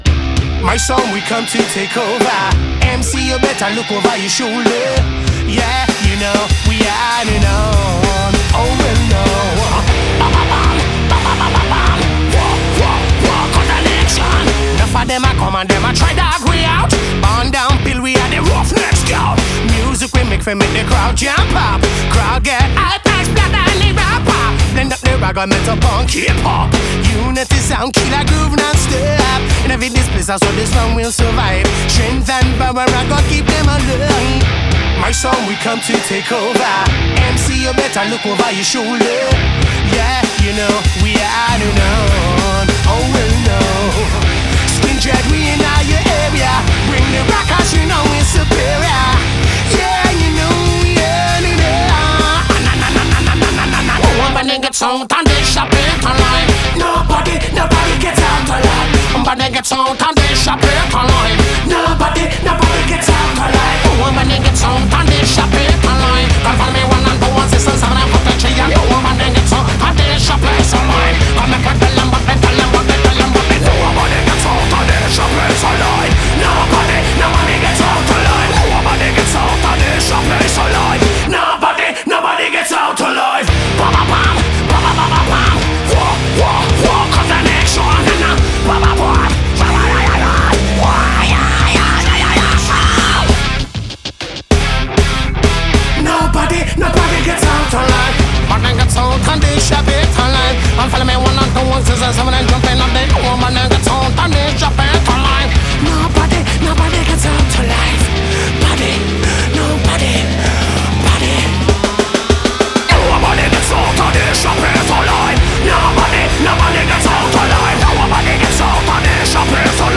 BPM98-196
MP3 QualityMusic Cut